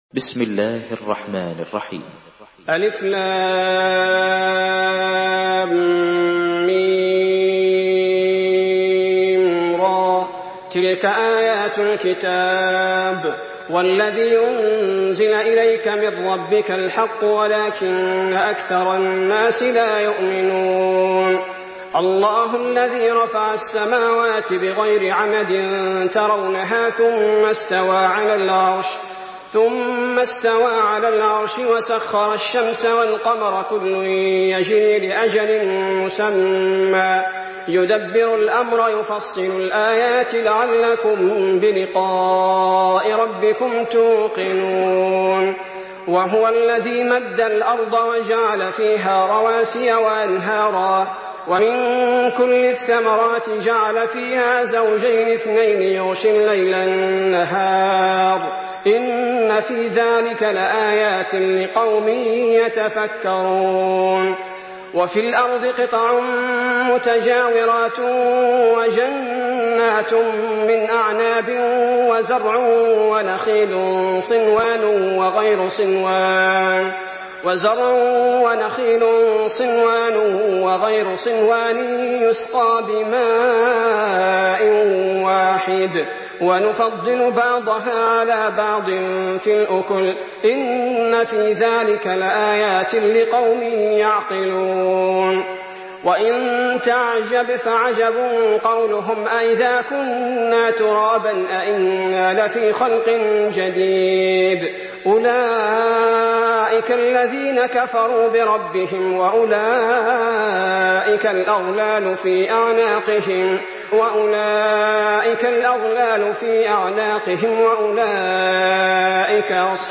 Surah Ar-Rad MP3 in the Voice of Salah Al Budair in Hafs Narration
Murattal Hafs An Asim